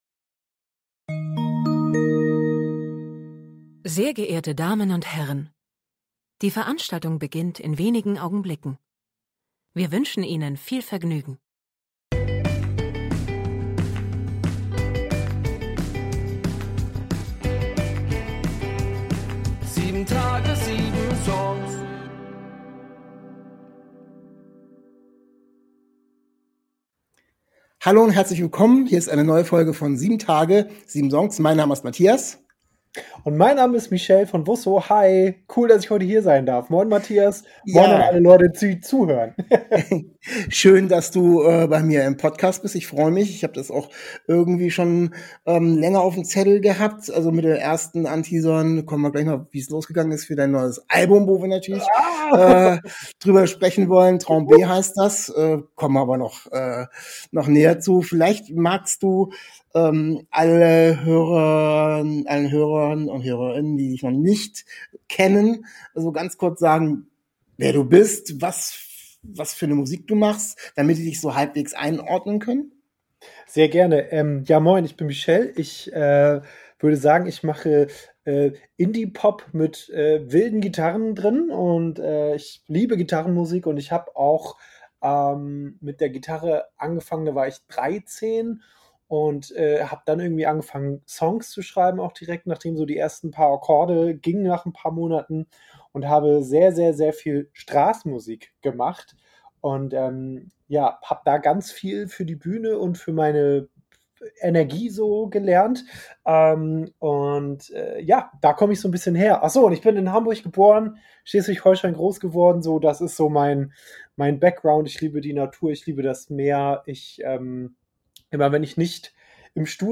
Zu Gast ist der Singer/ Songwriter